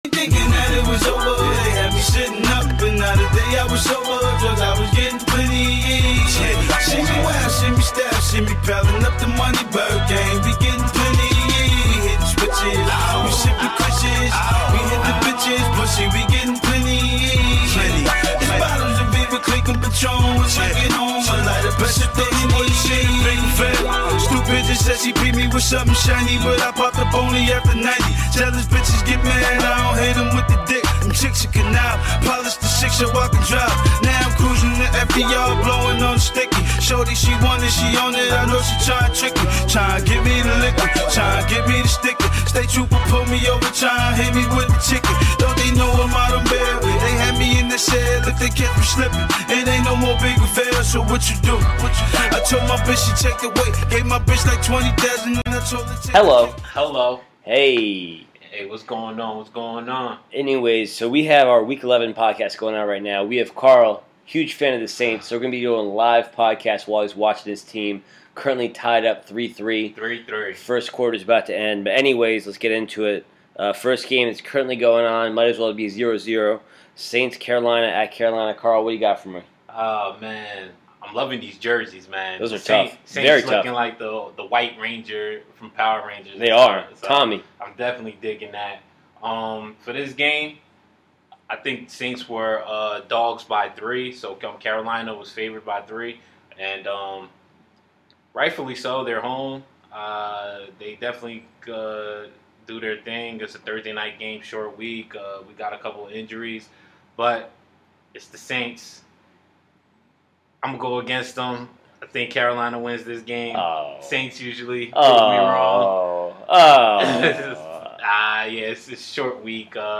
Listen to a live Podcast